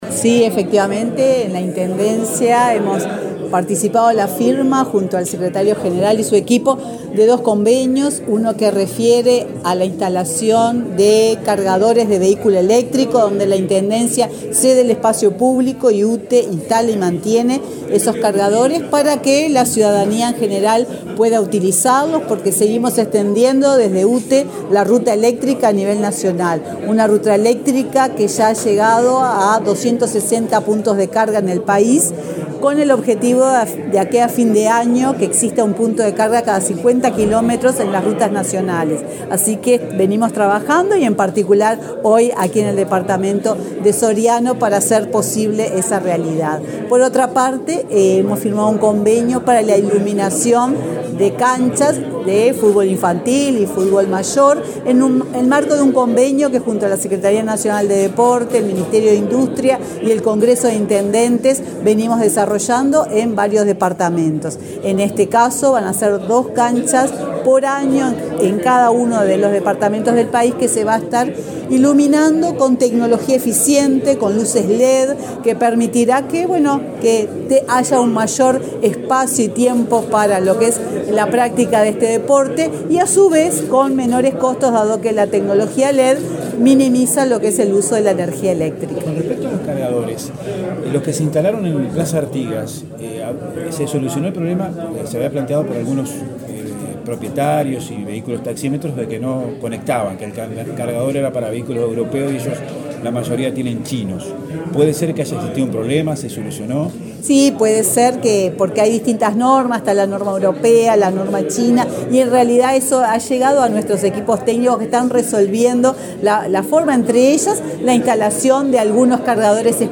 Declaraciones de la presidenta de UTE, Silvia Emaldi, a la prensa
La presidenta de UTE, Silvia Emaldi, dialogó con la prensa en Soriano, durante una recorrida por la ciudad de Mercedes, donde firmó un convenio con la